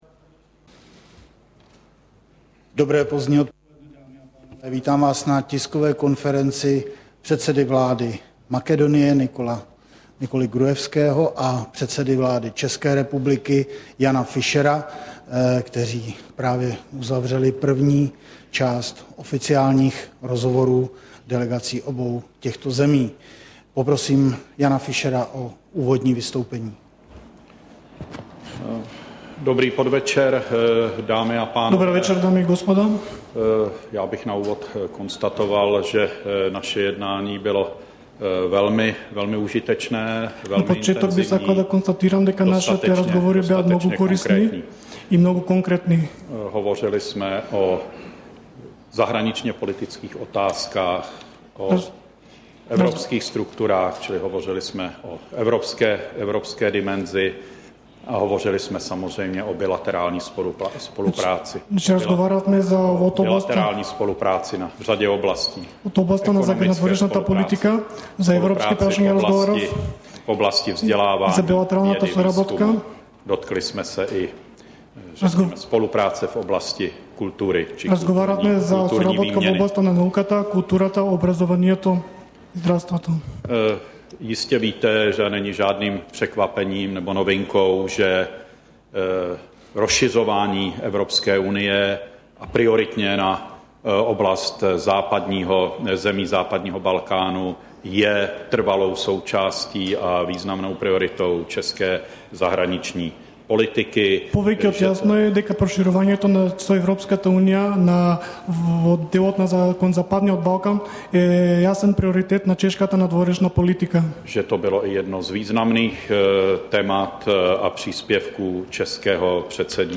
Tisková konference po jednání s předsedou vlády Makedonie, 9.2.2010